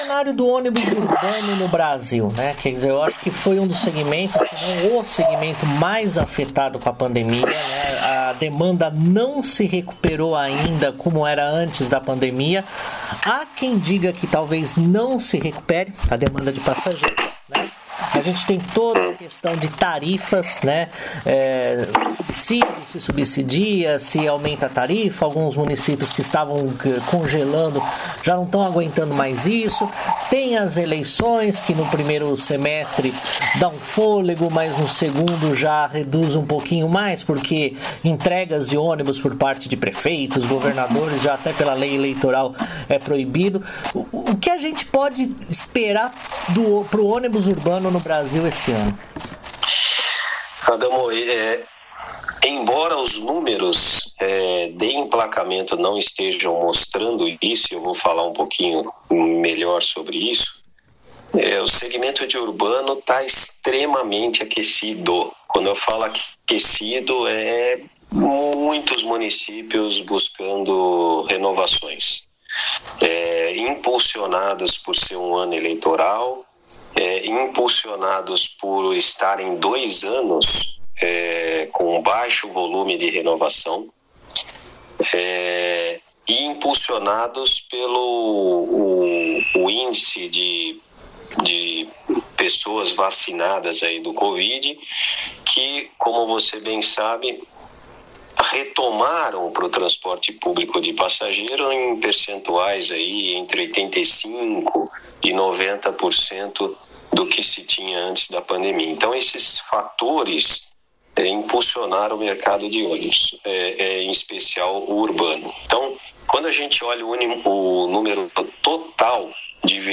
ENTREVISTA: Número de emplacamentos de ônibus não tem refletido a realidade do mercado por causa da falta de componentes